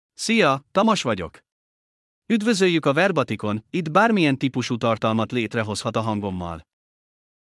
TamasMale Hungarian AI voice
Tamas is a male AI voice for Hungarian (Hungary).
Voice sample
Listen to Tamas's male Hungarian voice.
Tamas delivers clear pronunciation with authentic Hungary Hungarian intonation, making your content sound professionally produced.